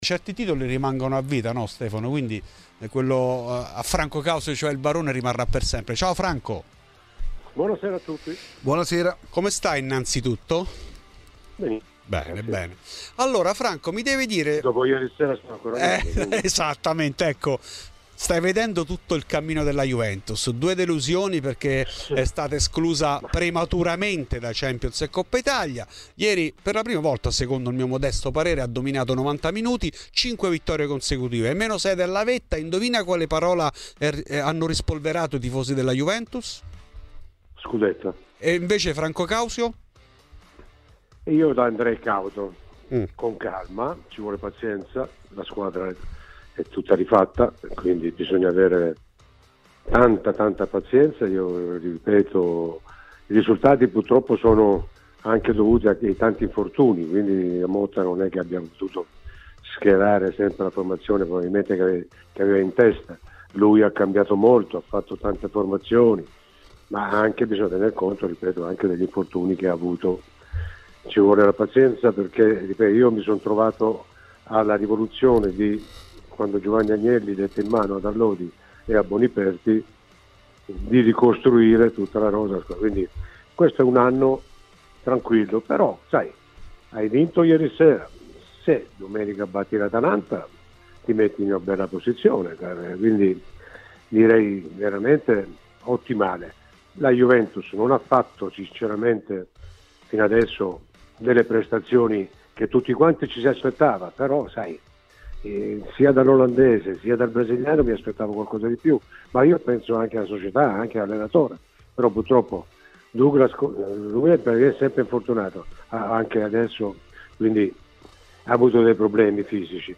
In ESCLUSIVA a Fuori di Juve Franco Causio Sul cammino della Juve e rispetto alla possibilità di tornare in corsa per lo scudetto, questo il parere del “Barone”: “Io sarei cauto.